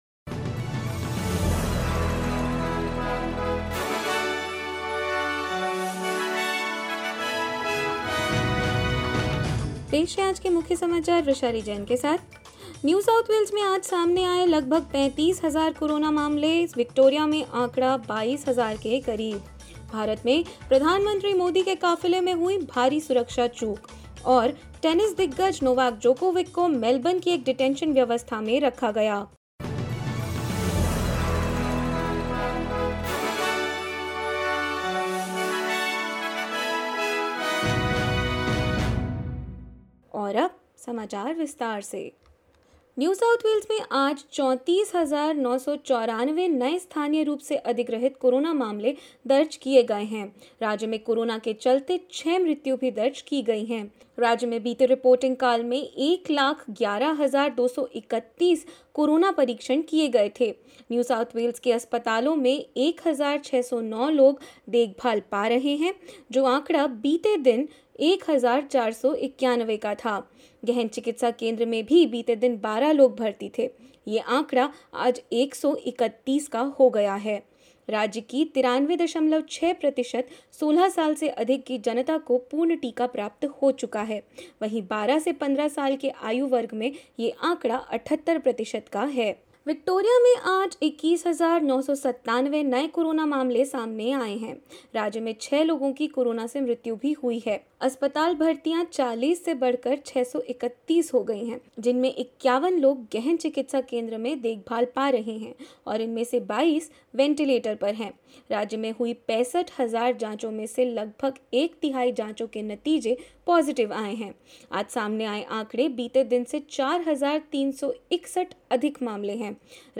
In this latest SBS Hindi news bulletin: New South Wales records nearly 35,000, new COVID-19 cases and Victoria, just under 22,000; Tennis player Novak Djokovic is being detained in a Melbourne immigration detention facility and more.